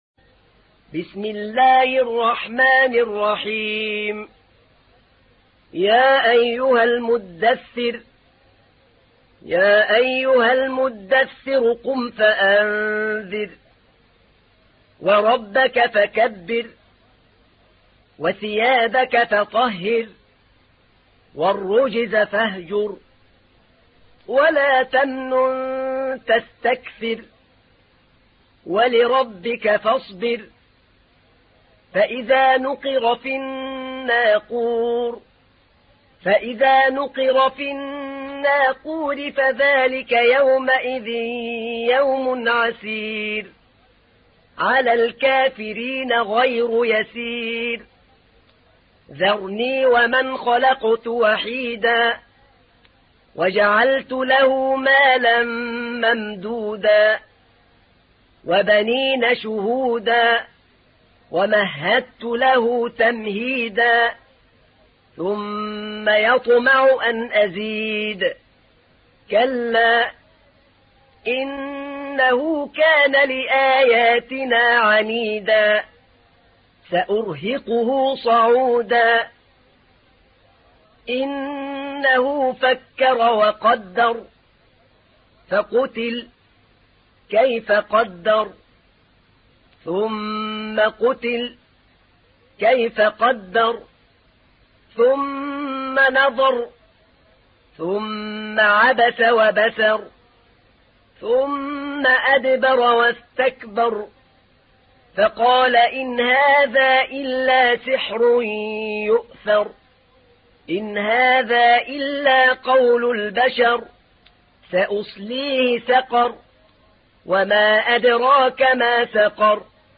تحميل : 74. سورة المدثر / القارئ أحمد نعينع / القرآن الكريم / موقع يا حسين